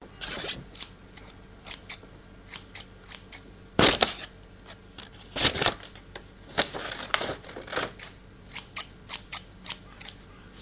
用剪刀切割
描述：剪刀切割的声音效果
Tag: 剪刀 切割 剪刀